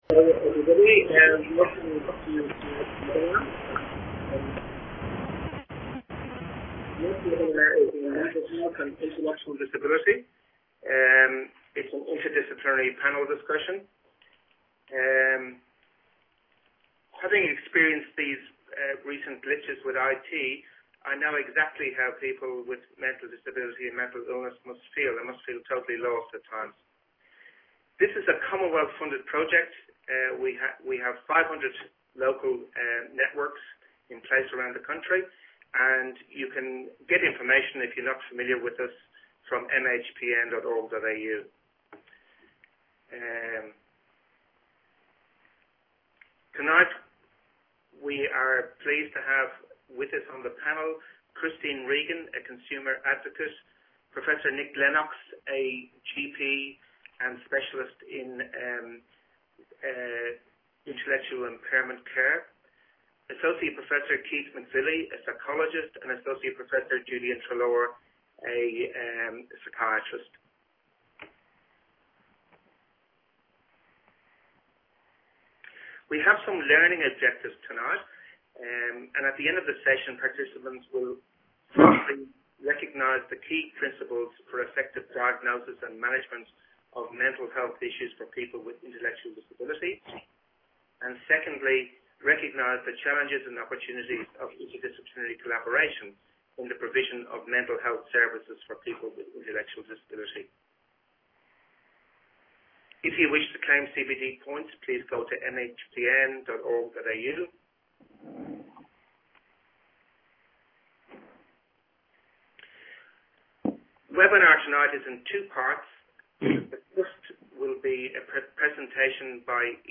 Mental Health and Intellectual Disability: An interdisciplinary panel discussion - MHPN
This webinar features a facilitated interdisciplinary discussion of a case study that addresses the key principles for effective diagnosis and management of mental health issues for people with intellectual disability.